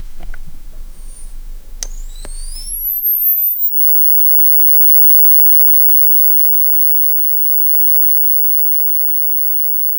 tv-turning-off-sound-el33rdbq.wav